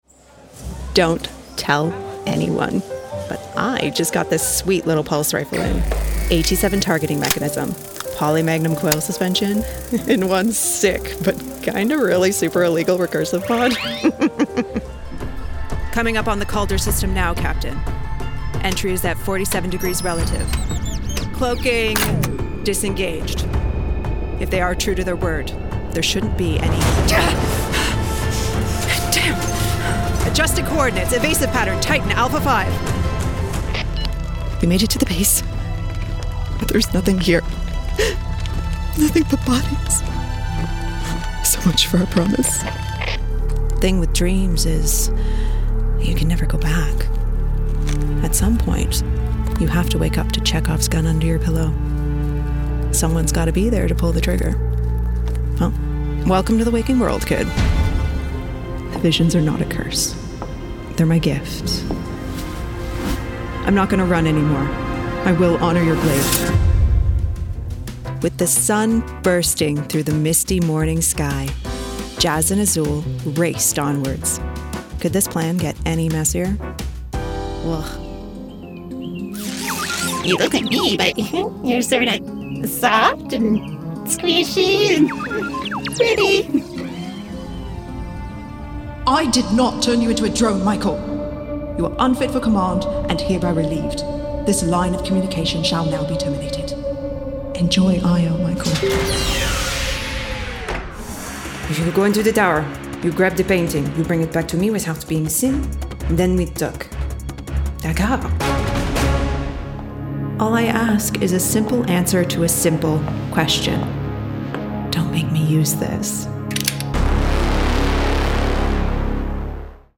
Englisch (Kanadisch)
Videospiele
Hauptmikrofon: Aston Spirit
Studio: Maßgeschallte, permanente Heimkabine (-79dB Geräuschpegel)
Im mittleren Alter